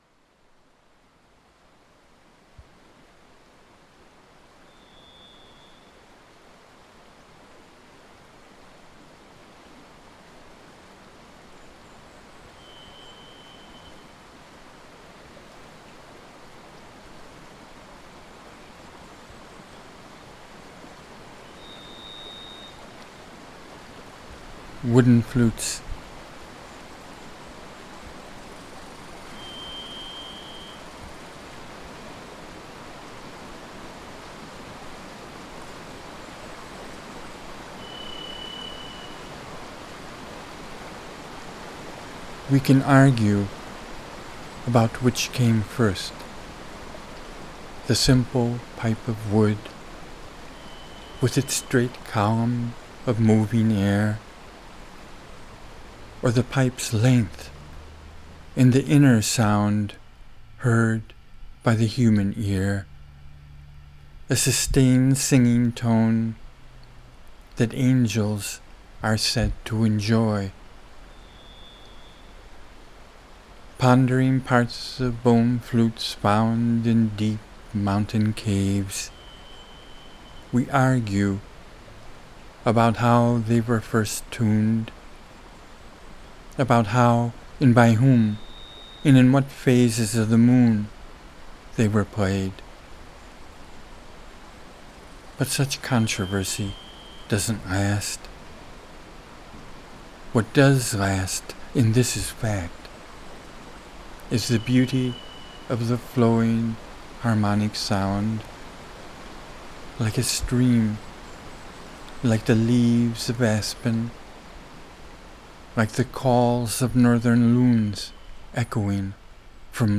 recorded in the Eagle Cap Wilderness w
a solo Varied Thrush in background |
wooden-flutes.mp3